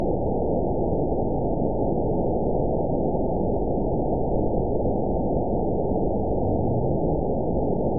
event 922699 date 03/12/25 time 20:10:30 GMT (9 months, 1 week ago) score 9.06 location TSS-AB10 detected by nrw target species NRW annotations +NRW Spectrogram: Frequency (kHz) vs. Time (s) audio not available .wav